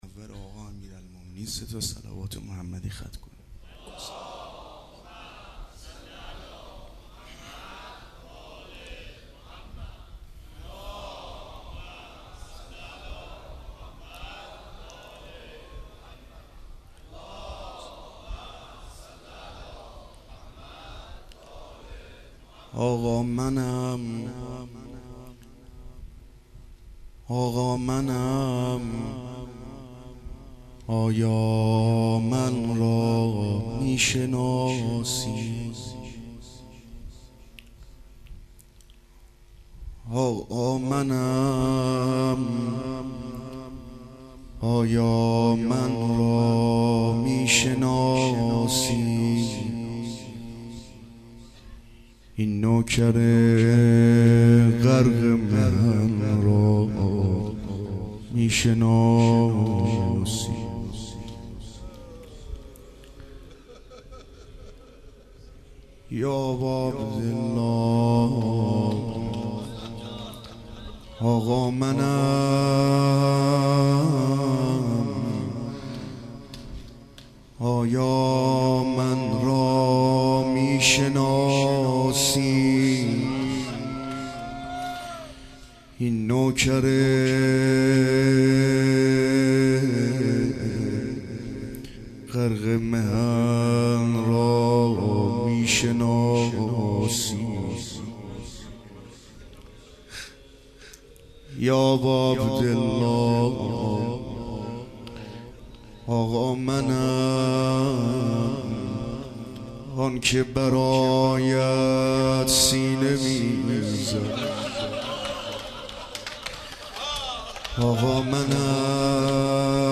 مراسم هفتگی هیئت الرضا با مداحی حاج رضا هلالی برگزار شد.
روضه